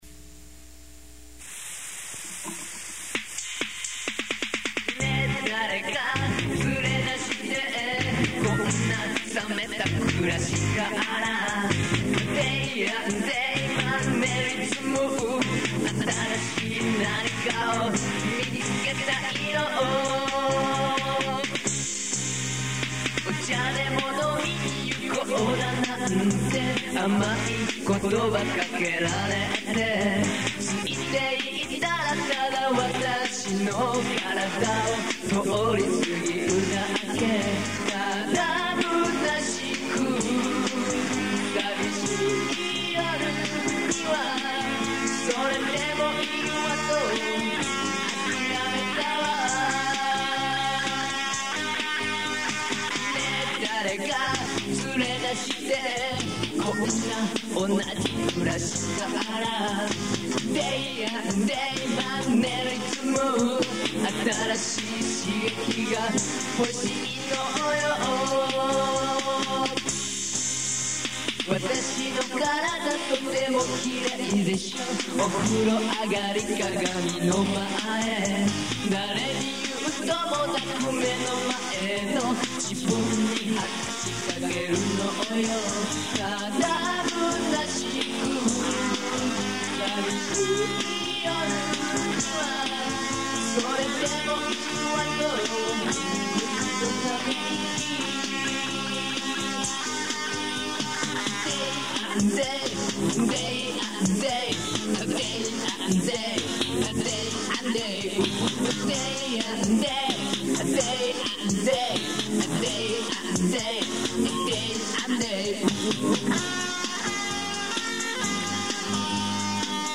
唄
ギター
ベース
ピアノ
キーボード
ドラムプログラム